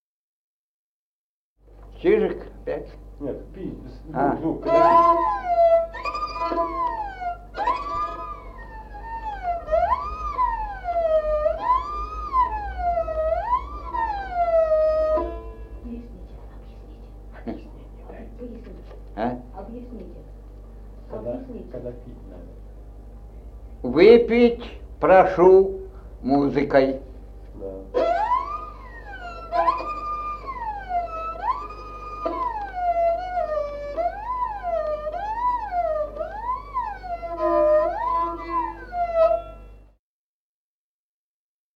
Музыкальный фольклор села Мишковка «Выпить прошу музыкой», репертуар скрипача.